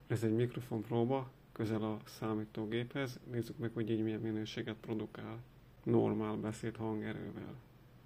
A mikrofon
A minősége teljesen rendben van, jól visszaadja a beszéd eredeti hangzását és érzékenysége is OK.
Minta nem nagyon zajos, de nem is néma számítógép mellett